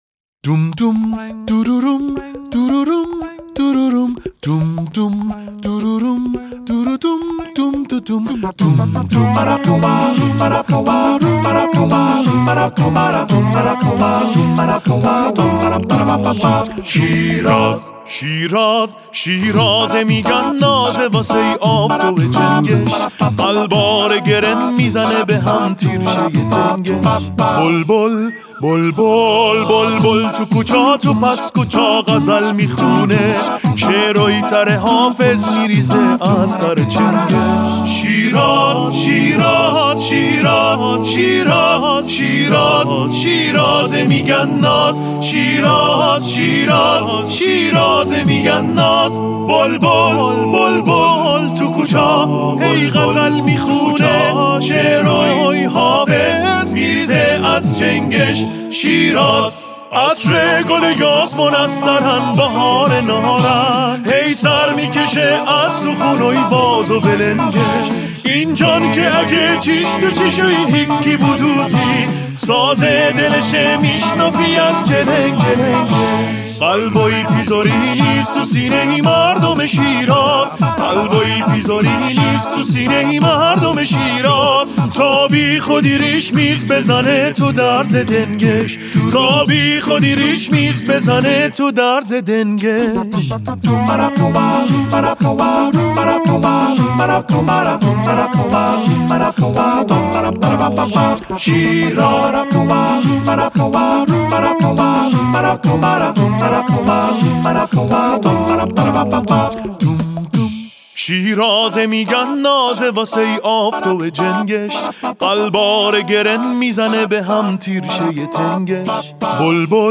این دو نمونه اش (آکاپلا)